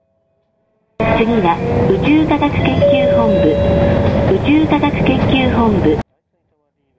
bus_at_ISAS.mp3